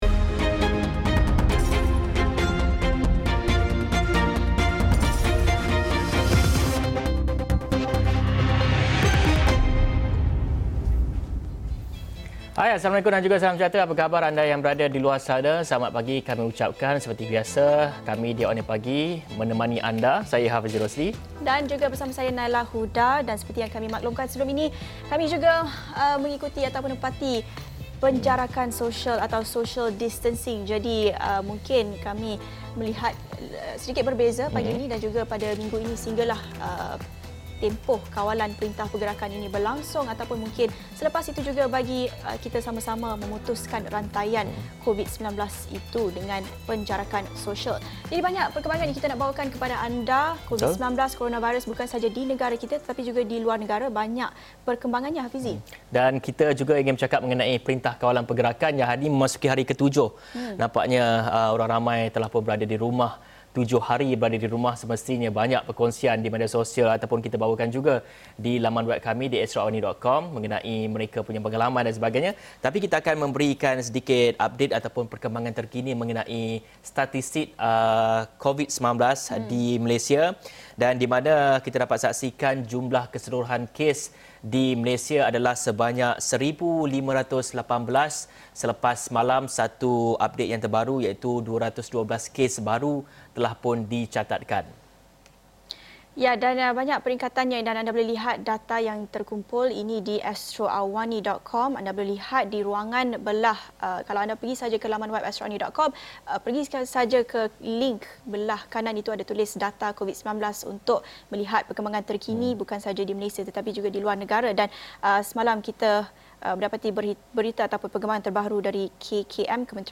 Berita kemaskini COVID-19 [24 Mac 2020]